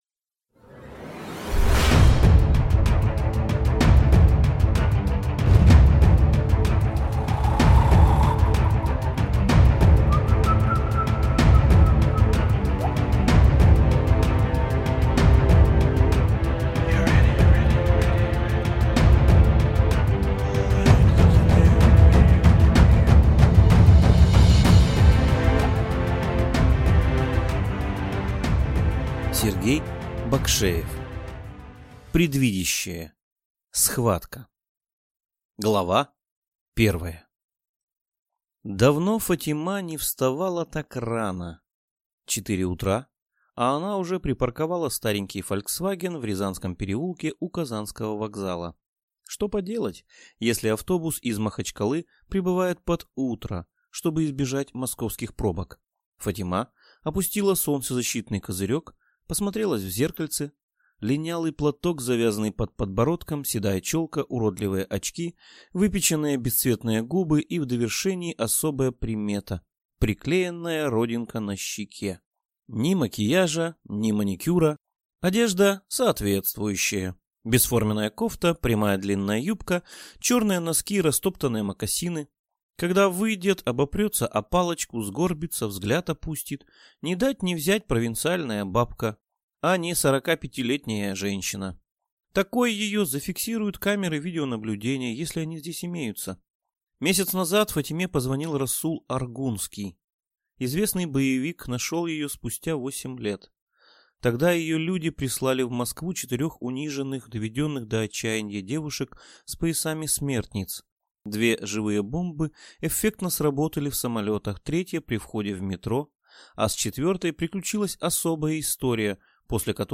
Аудиокнига Предвидящая: схватка | Библиотека аудиокниг